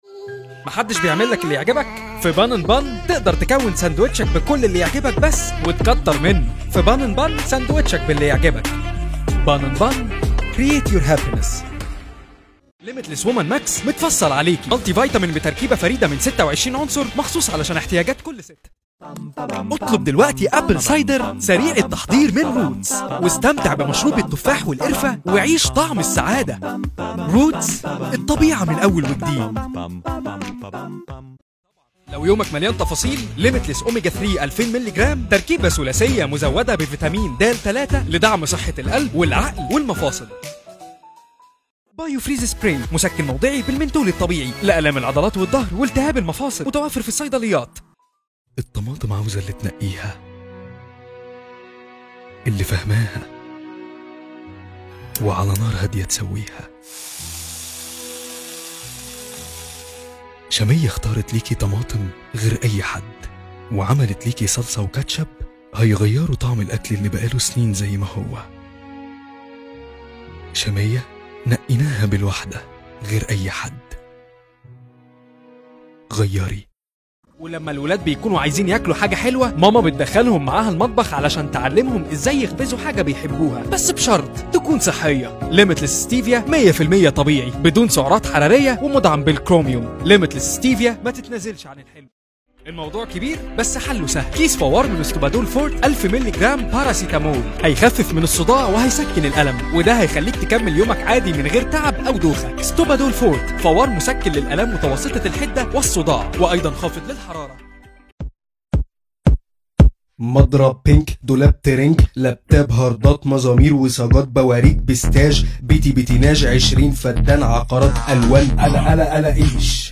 Male Voices